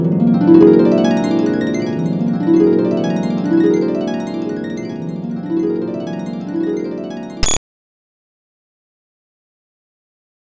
HARP.WAV